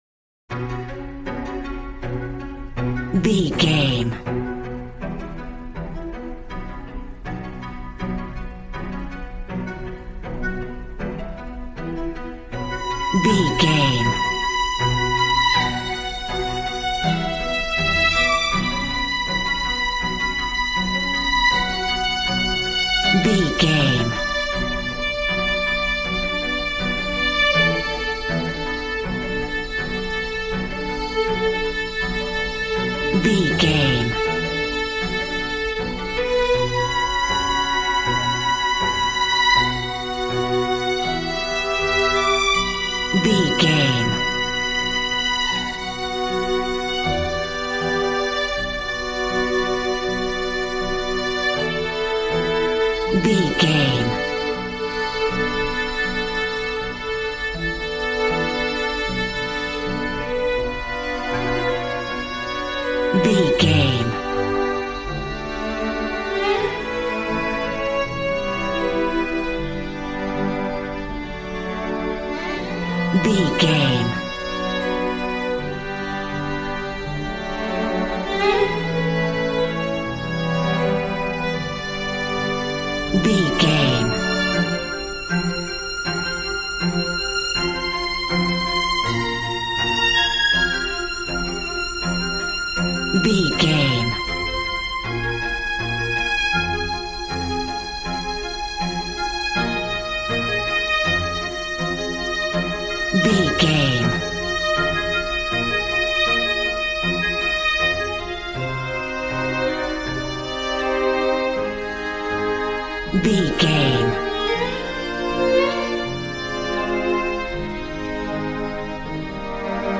Aeolian/Minor
regal
cello
violin
brass